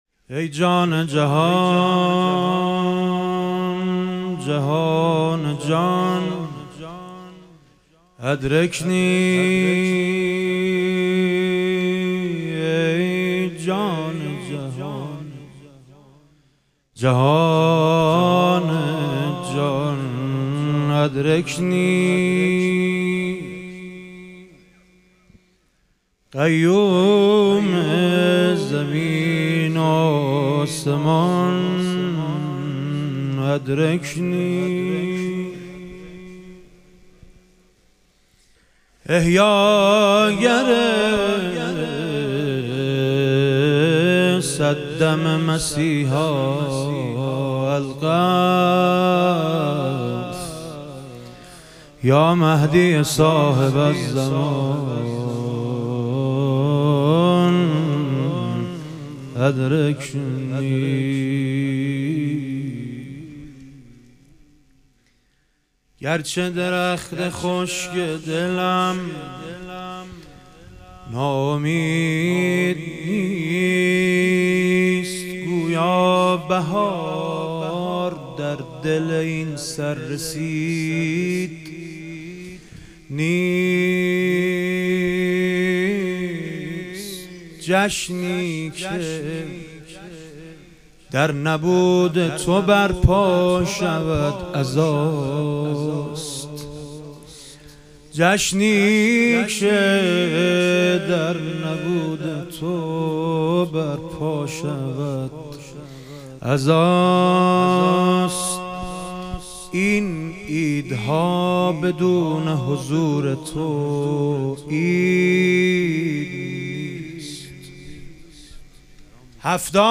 ظهور وجود مقدس امام جواد و حضرت علی اصغر علیهم السلام - مدح و رجز